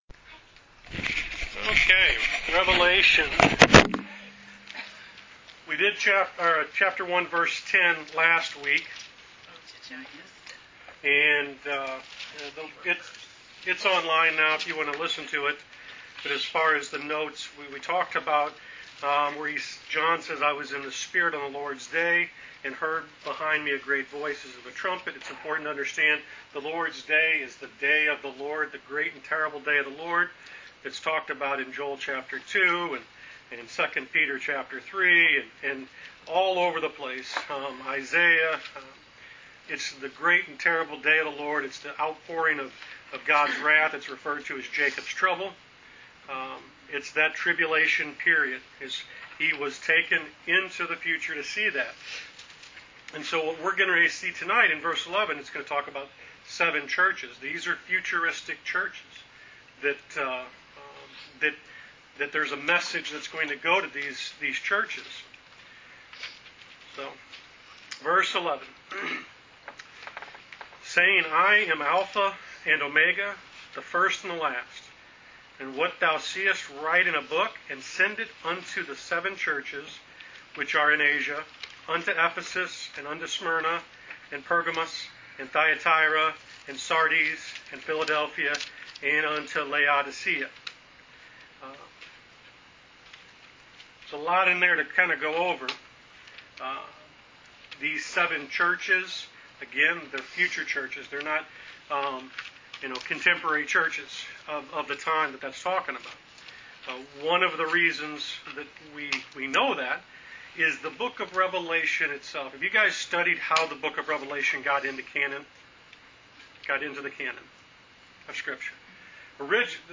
Wednesday Bible Study: Rev Ch 1 Pt 4